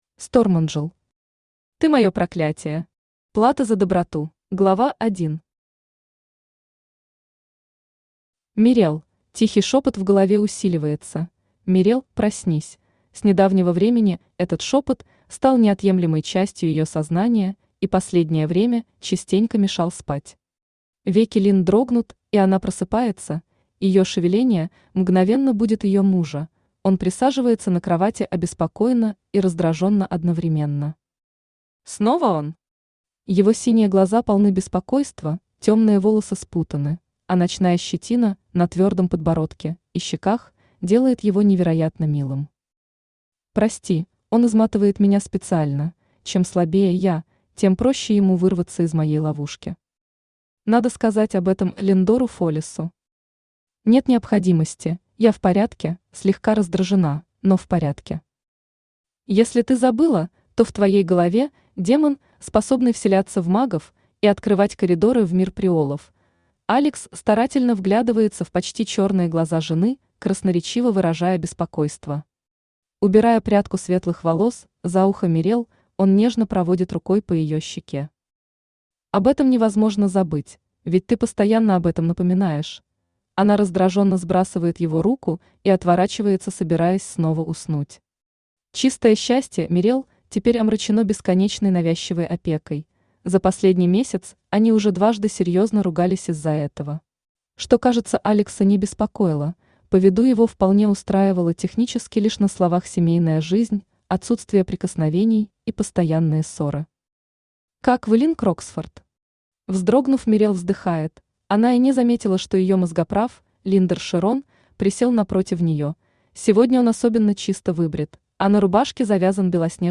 Аудиокнига Ты мое проклятие. Плата за доброту | Библиотека аудиокниг
Плата за доброту Автор Stormangel Читает аудиокнигу Авточтец ЛитРес.